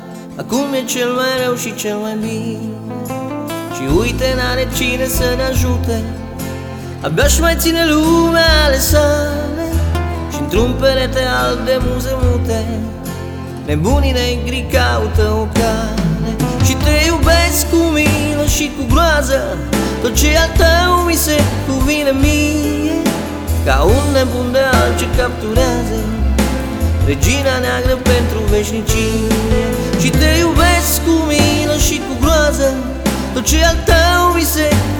Жанр: Фолк-рок / Альтернатива
# Alternative Folk